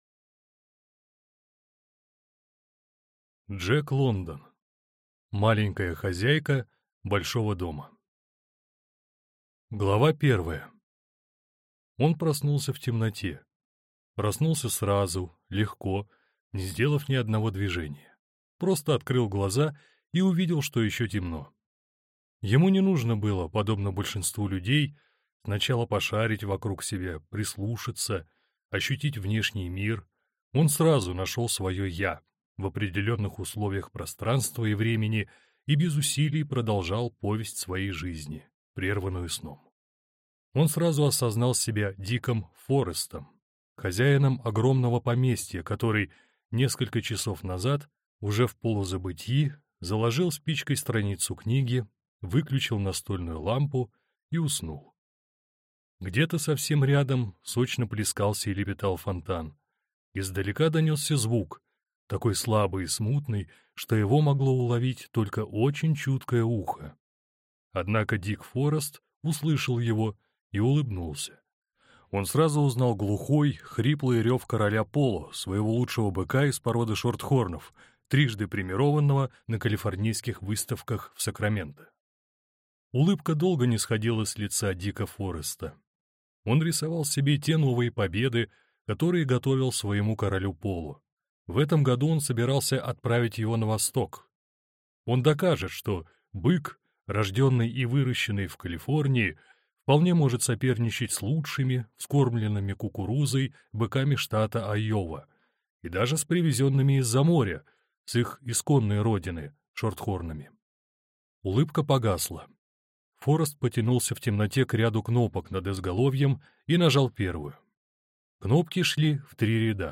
Аудиокнига Маленькая хозяйка Большого дома | Библиотека аудиокниг